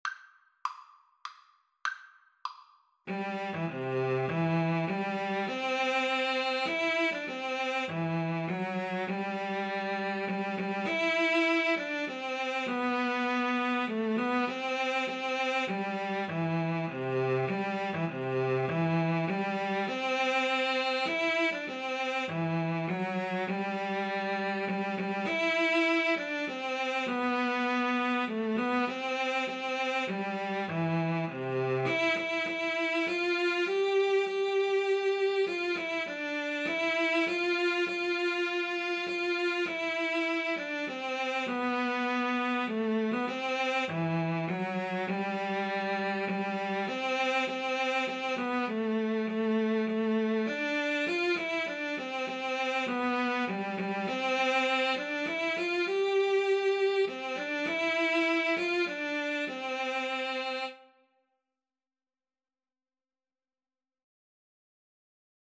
Cello 1Cello 2
3/4 (View more 3/4 Music)